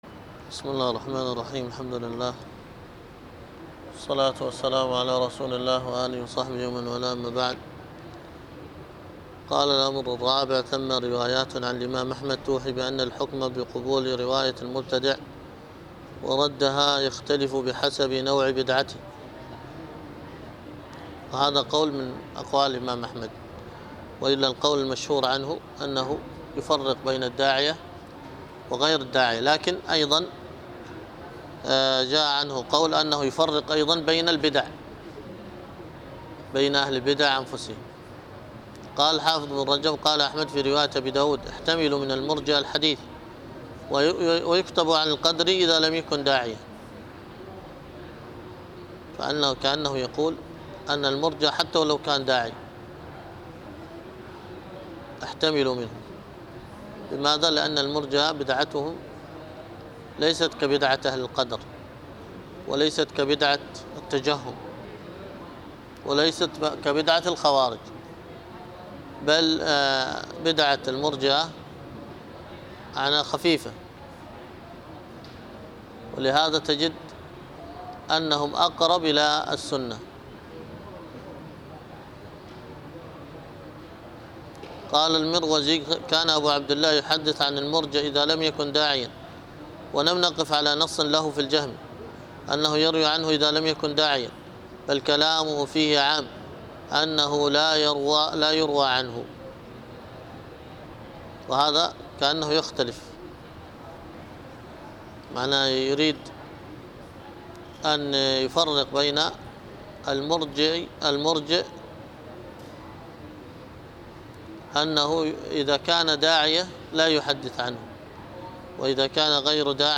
الدرس في كتاب البيع 7، ألقاها